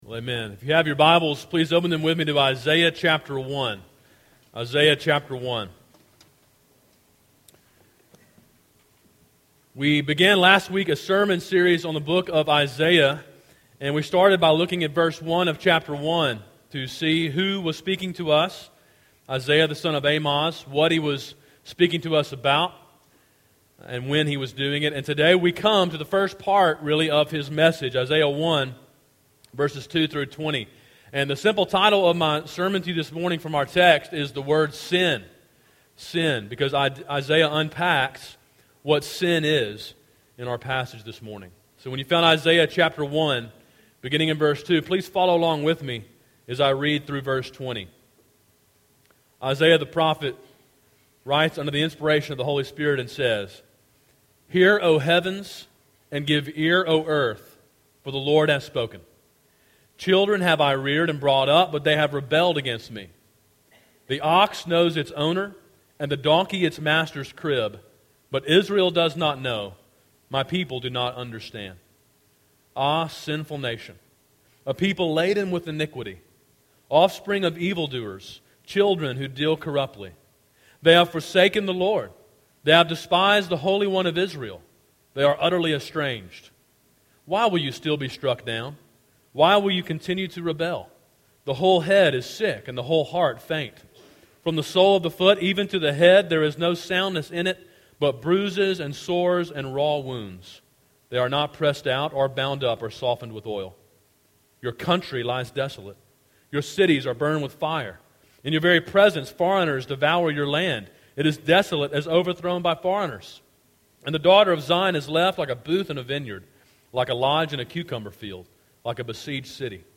Sermon: “Sin” (Isaiah 1:2-20)
Sermon in a series on the book of Isaiah.